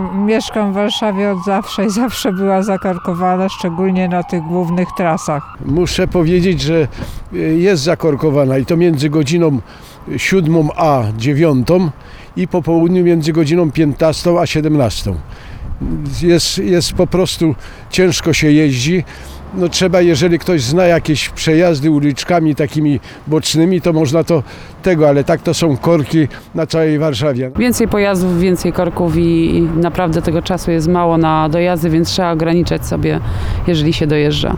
O utrudnione poruszanie się autem po stolicy zapytaliśmy kierowców: